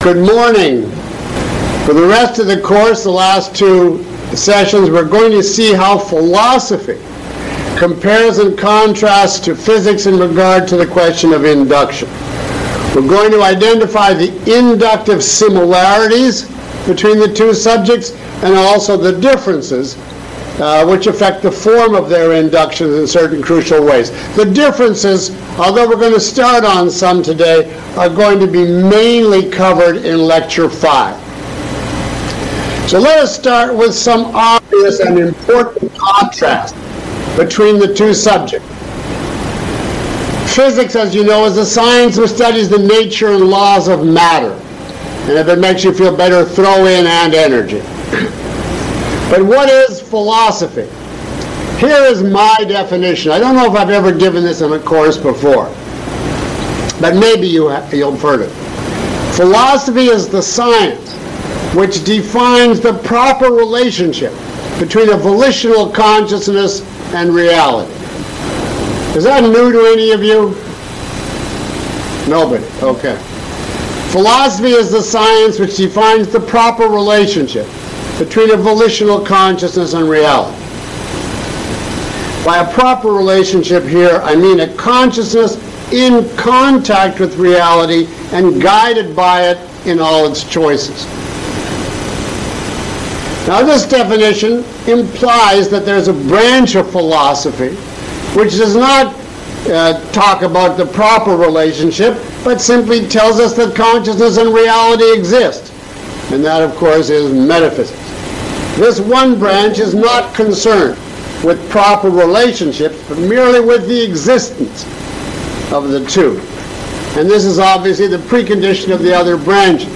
[Lecture Six] Induction in Physics and Philosophy